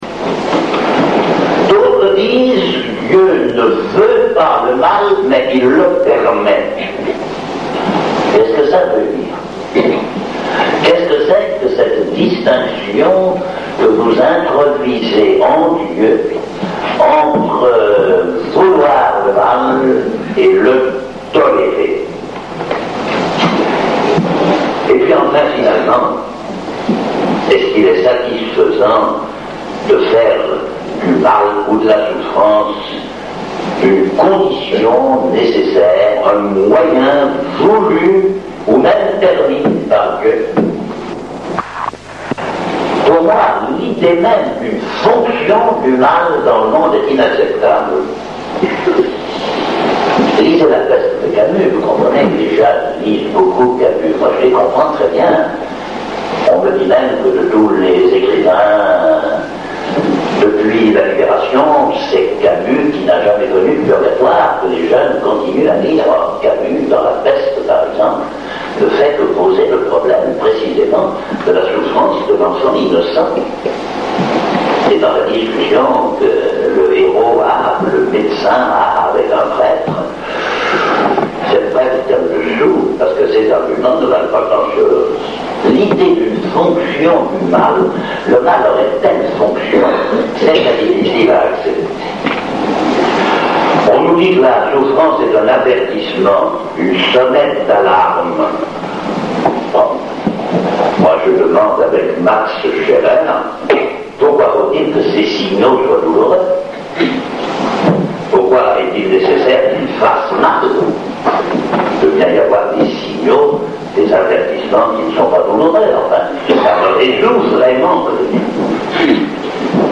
Conférence